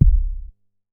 MoogAmigo E.WAV